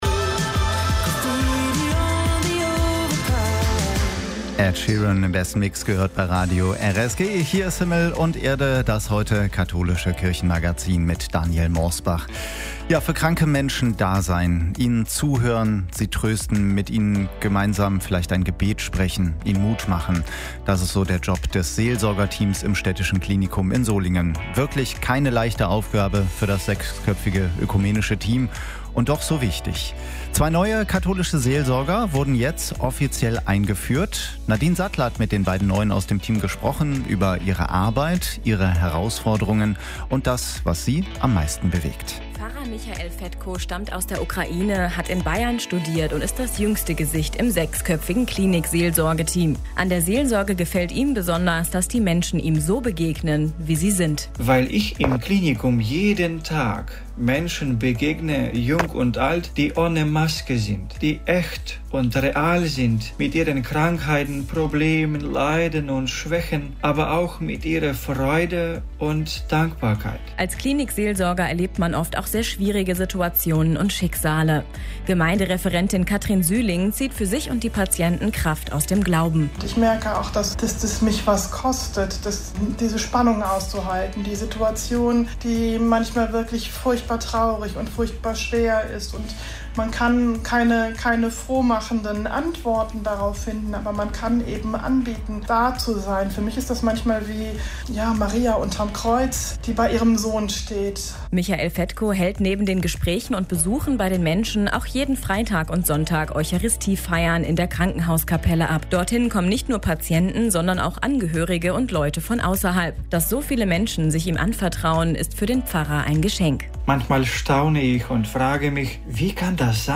Wir haben mit ihnen gesprochen über das, was sie am meisten an dieser Aufgabe herausfordert und bewegt.